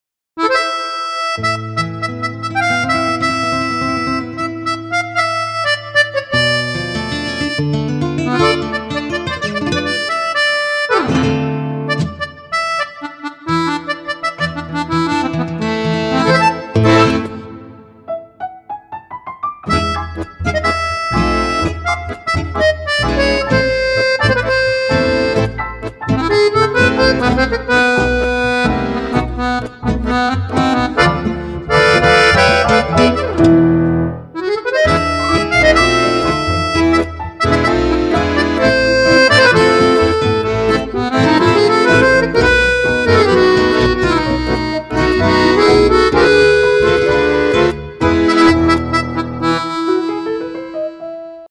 类型： 手风琴音源
音源音色试听（ 真的太丝滑了 ）